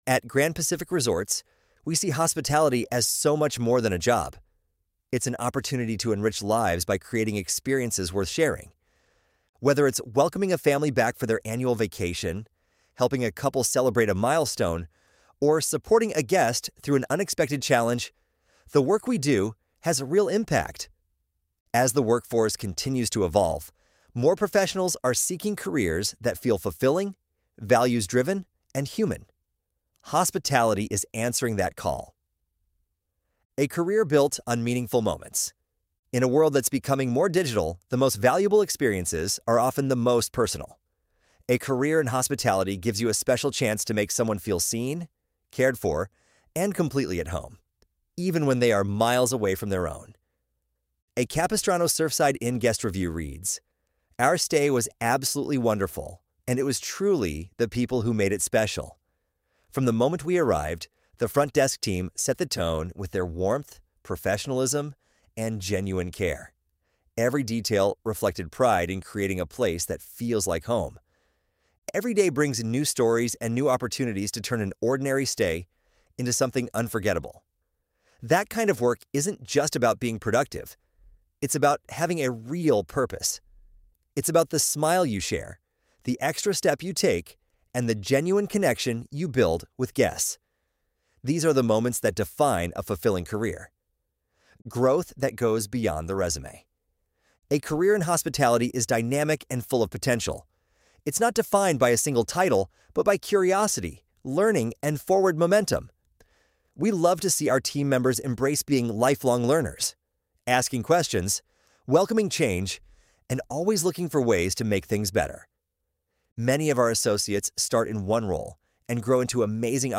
ElevenLabs_Untitled_project-6.mp3